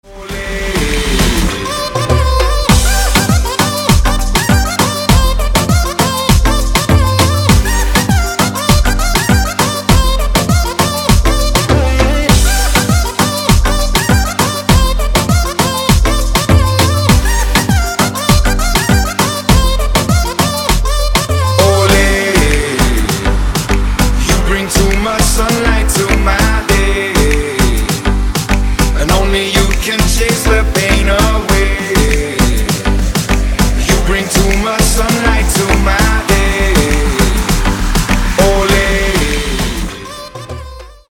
• Качество: 192, Stereo
dance
Electronic
Trap
club